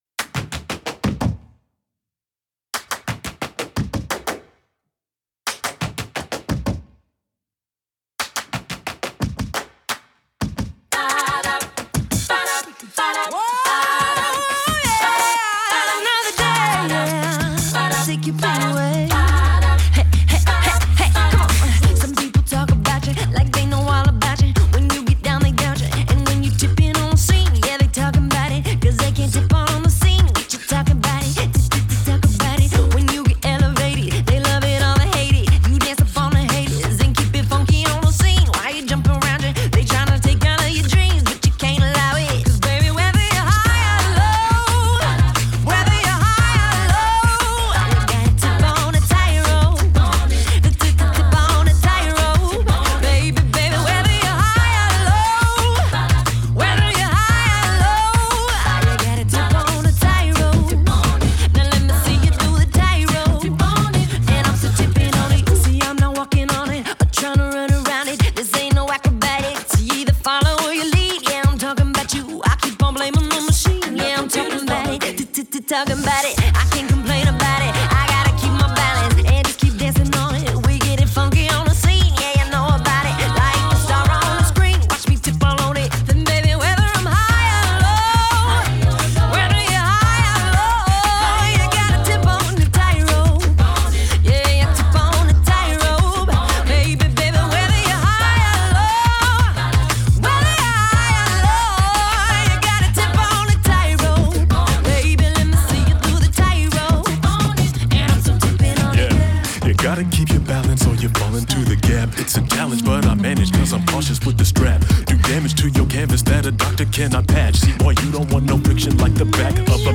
Genre: Pop, Classical